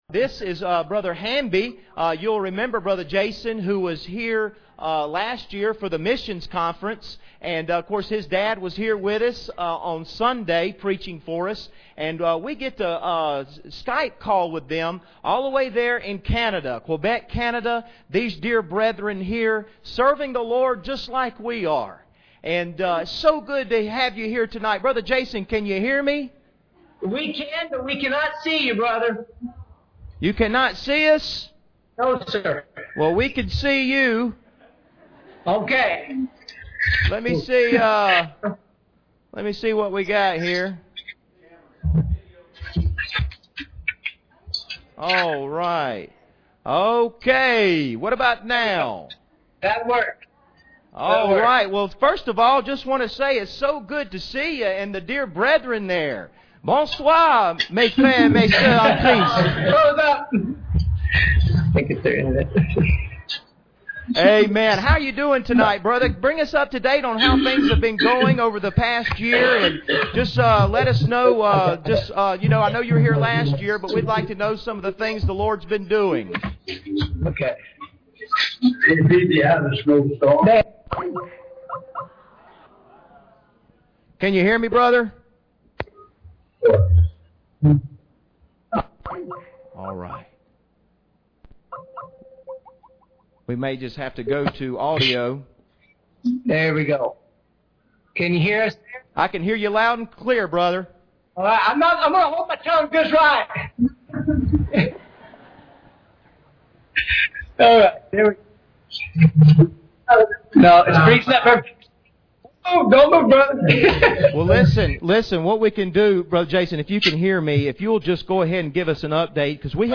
2016 Missions Conference Service Type: Special Service Preacher